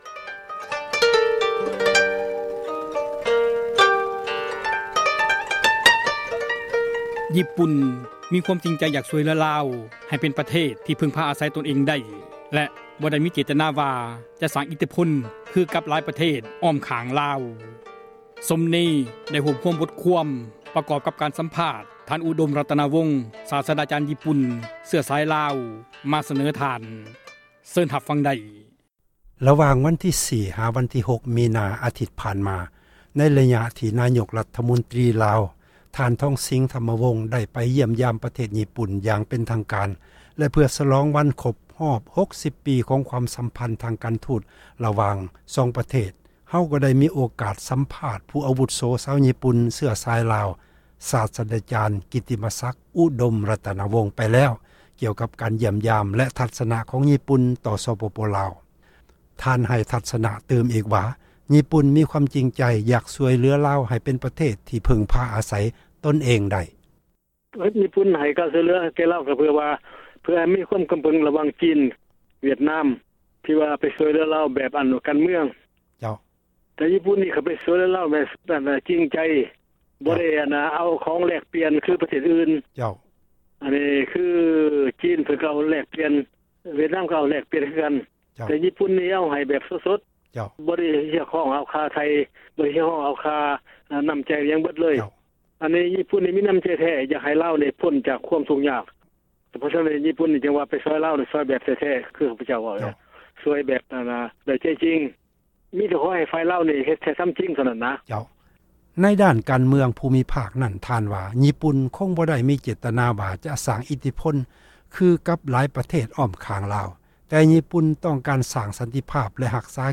ການ ສໍາພາດ